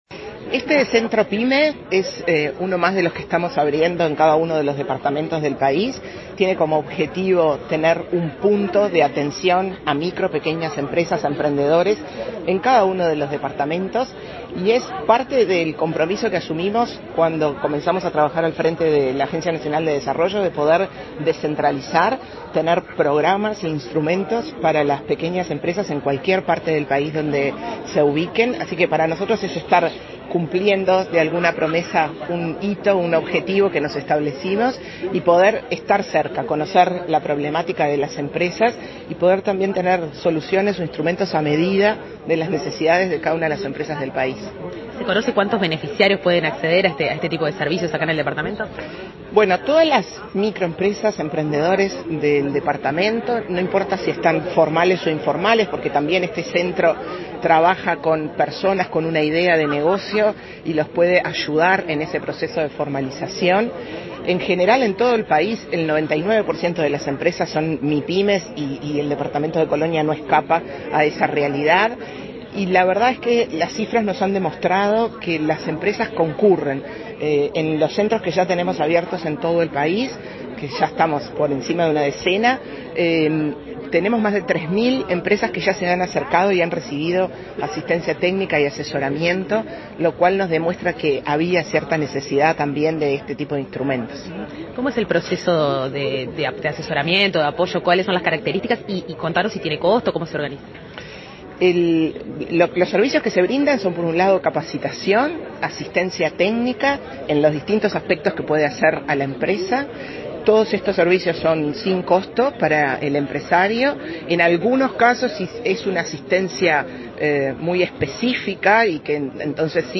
Entrevista a la presidenta de ANDE, Carmen Sánchez